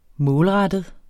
Udtale [ -ˌʁadəð ]